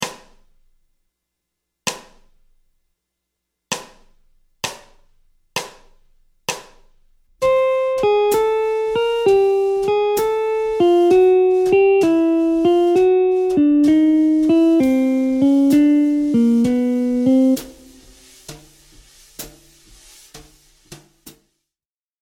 Mode Lydien b7 ( IV mineur mélodique)
Descente de gamme
Gamme-bop-desc-Pos-31-C-Lydien-b7-1.mp3